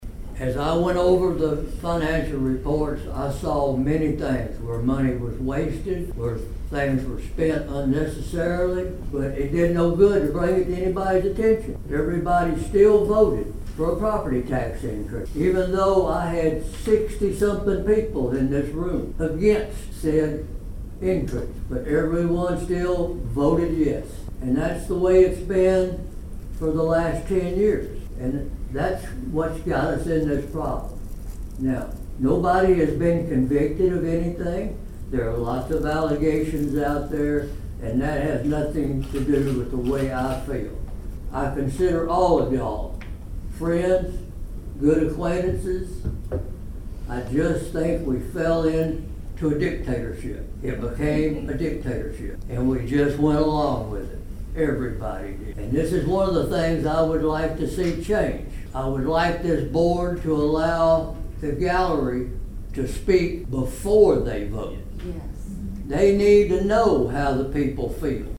Approximately 75 community members attended  a special called Martin City Board Meeting  last night at the  Martin Municipal Building where acting Mayor David Belote held an open forum to allow residents who share their concerns about City Hall.